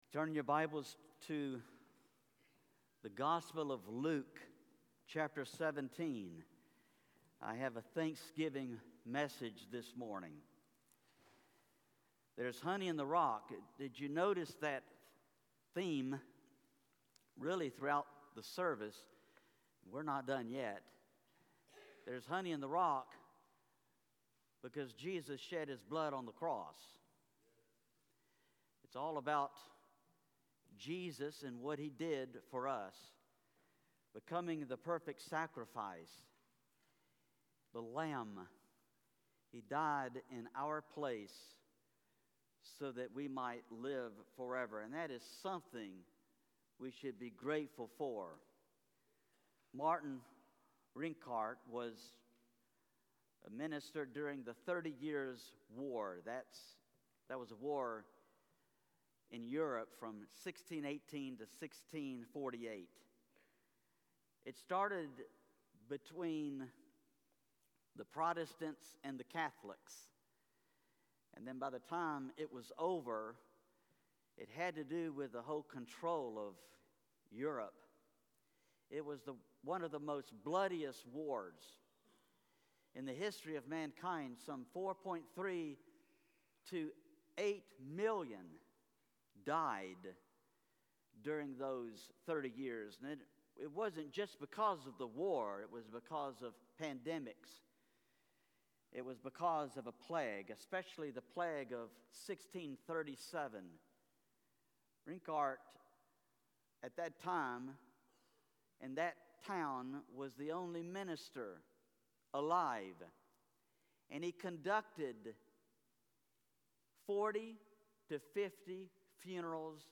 Sermons | Rowland Springs Baptist Church
To hear the rest of the sermon and the last song, play the mp3.